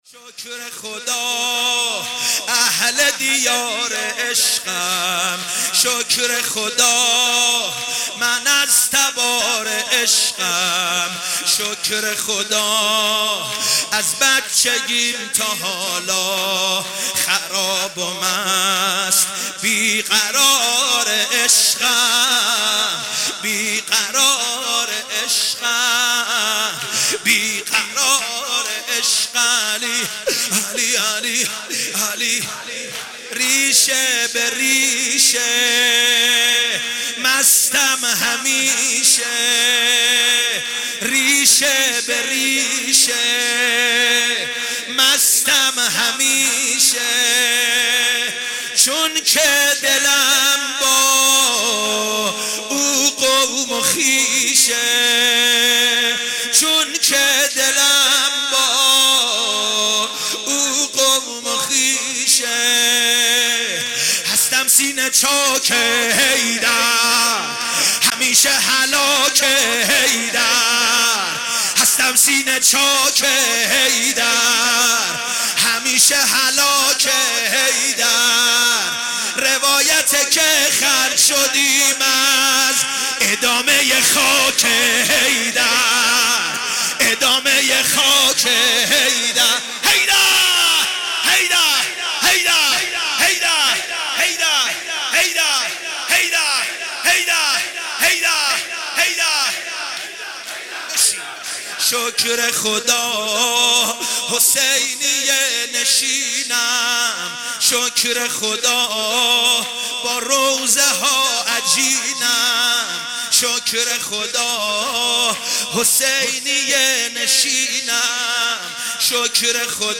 مداحی
در شب 19 رمضان